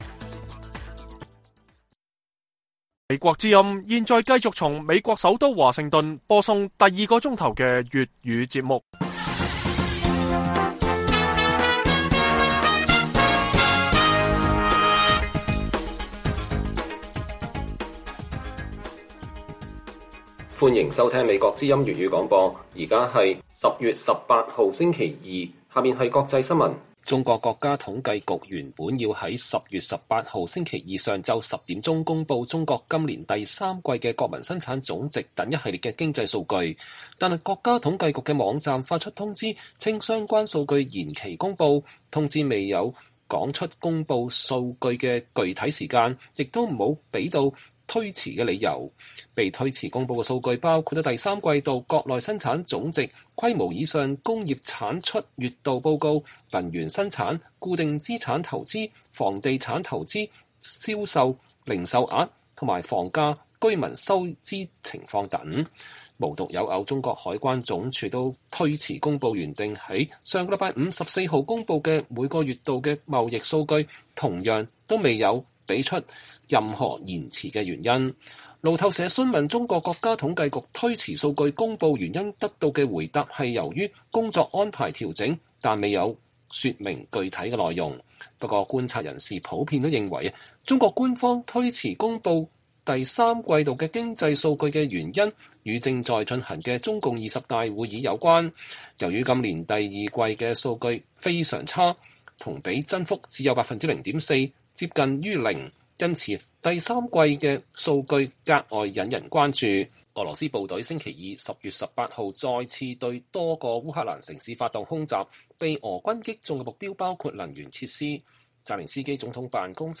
粵語新聞 晚上10-11點: 中國國家統計局無限期推遲公佈三季GDP